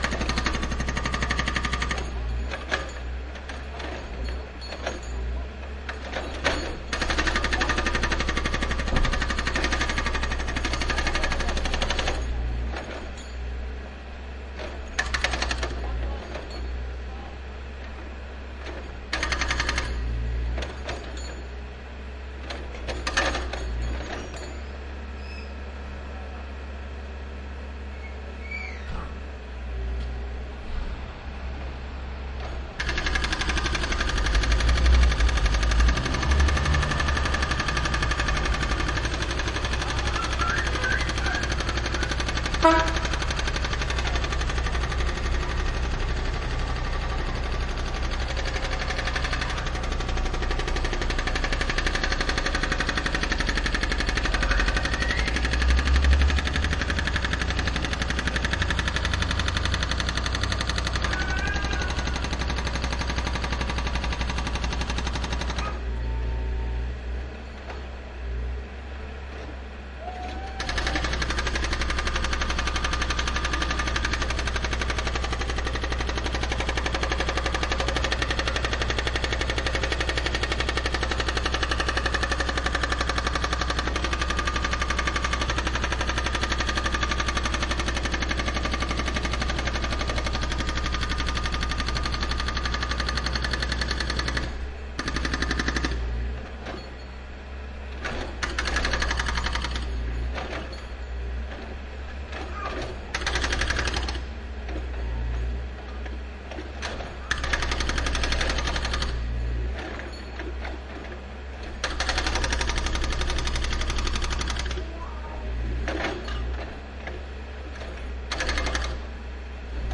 锤子
描述：记录在温哥华BC公寓楼后面的手提钻。用索尼PCMD50录制。电机，车辆和儿童的偶然声音。
Tag: 机械 机械 建筑 机器 工业